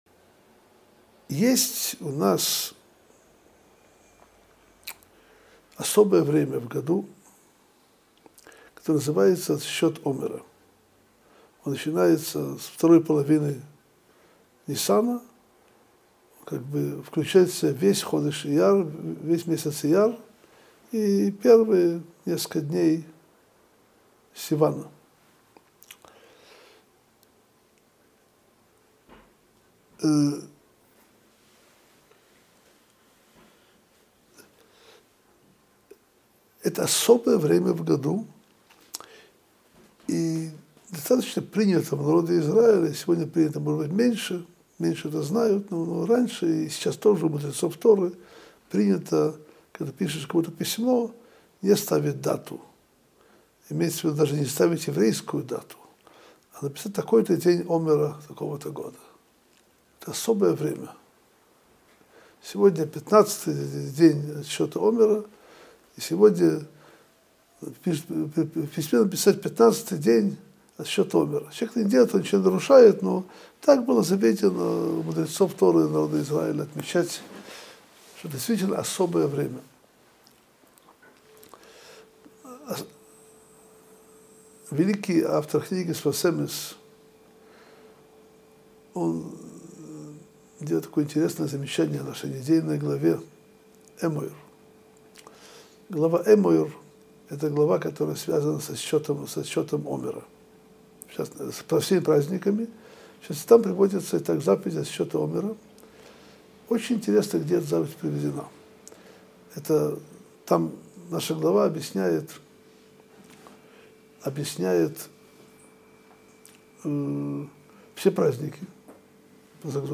Содержание урока: Как обычно датируют письма написанные в дни отсчета Омера? Почему Шавуот – это день суда?